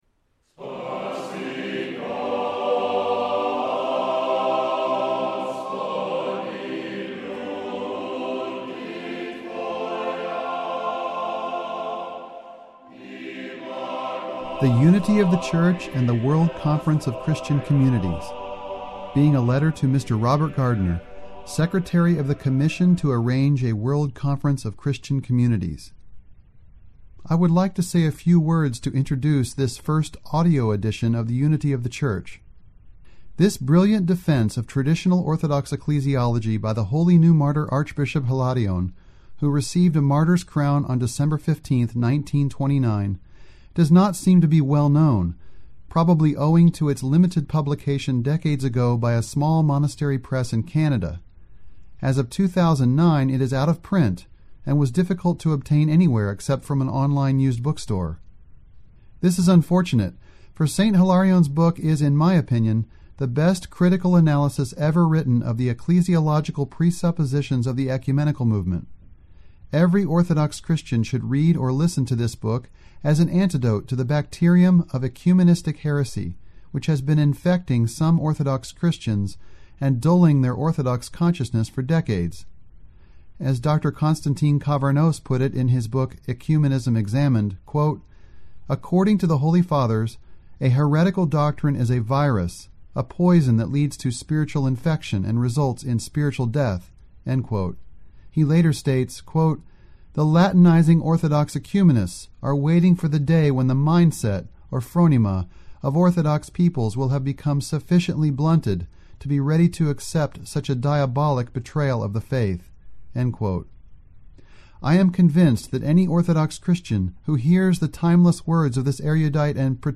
This is the first audio book offered by the Orthodox Christian Information Center.